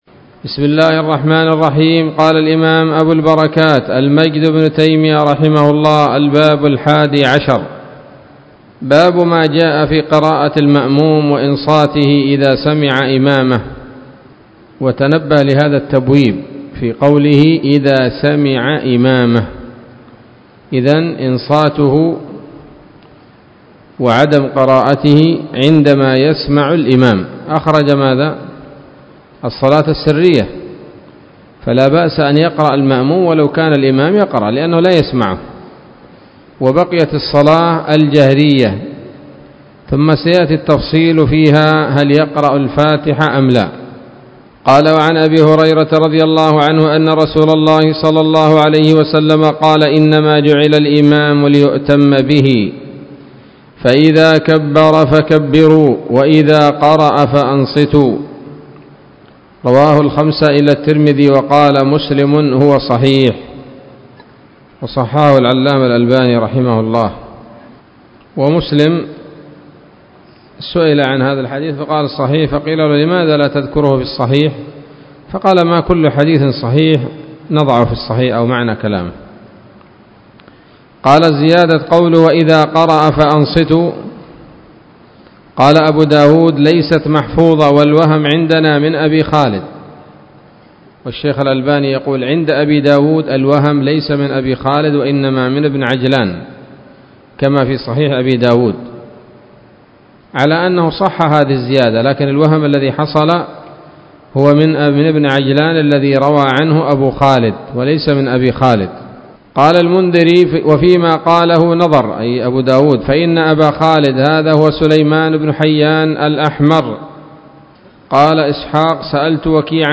الدرس الثاني والثلاثون من أبواب صفة الصلاة من نيل الأوطار